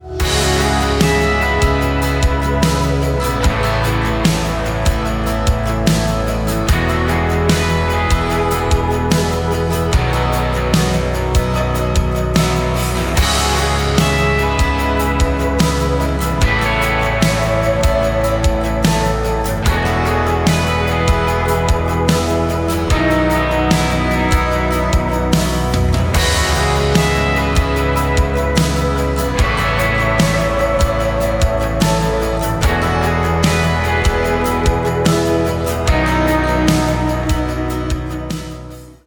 Нарезка на смс или будильник